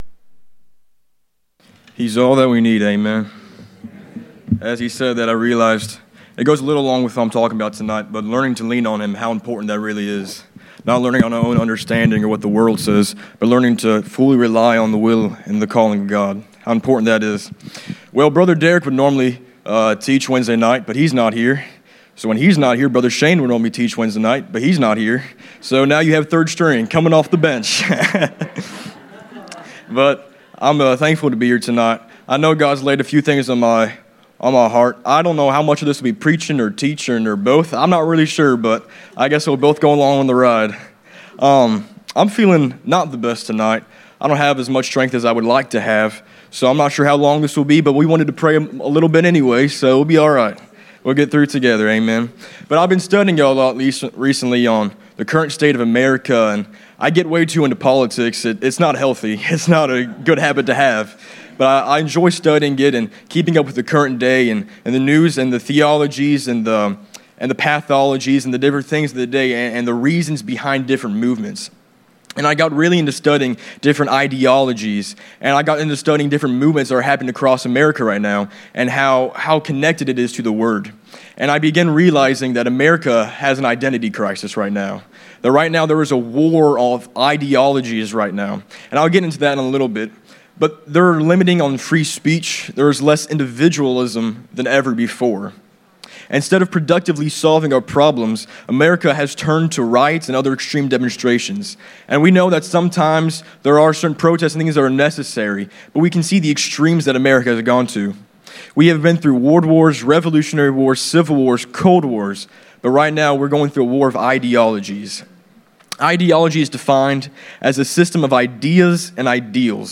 Service Type: Midweek Meeting